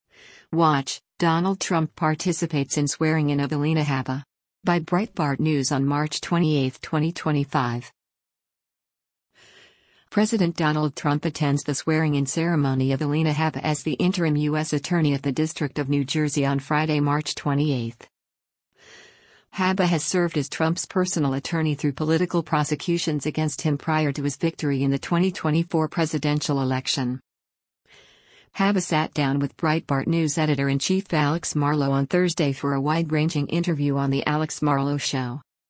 President Donald Trump attends the swearing in ceremony of Alina Habba as the interim U.S. Attorney of the District of New Jersey on Friday, March 28.